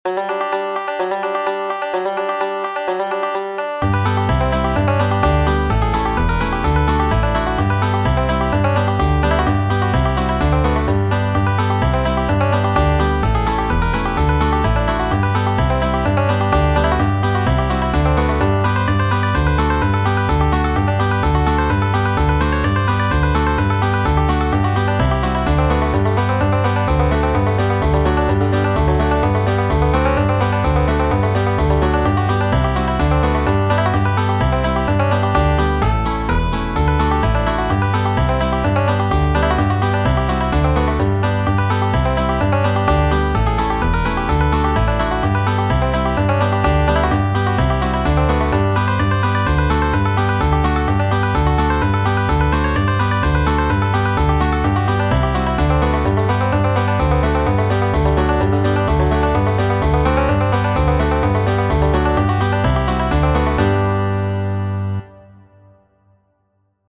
Muziko:
originala ludopeco por banjo kaj gitaro